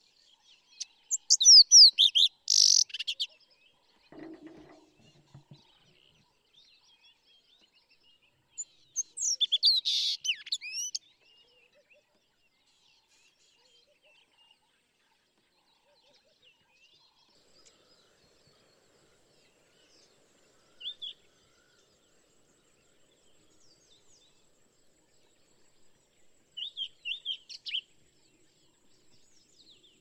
agrobate-roux.mp3